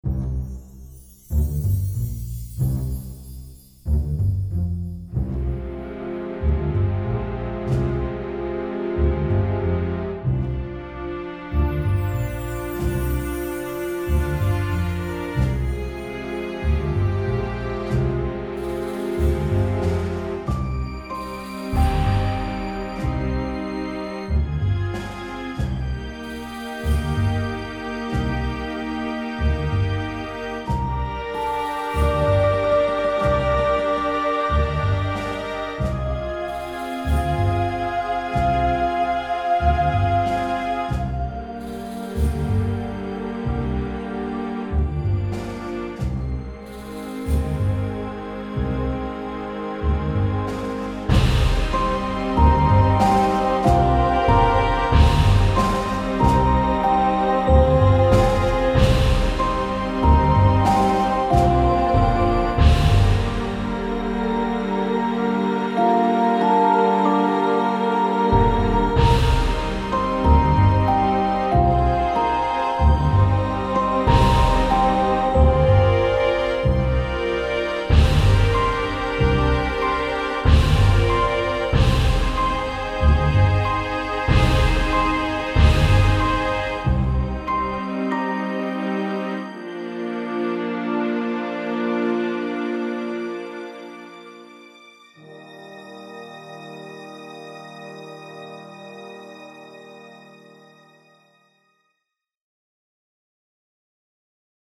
De jolies compositions ambient !
Dommage que les cordes ne vivent pas (manque courbes d'automation). Un peu mécanique (évite les boucles à l'identique)
Belles entrées de cordes. Peu évolutif.
Un peu trop martial pour un prière ; la snare drum et les piatti y sont pour beaucoup.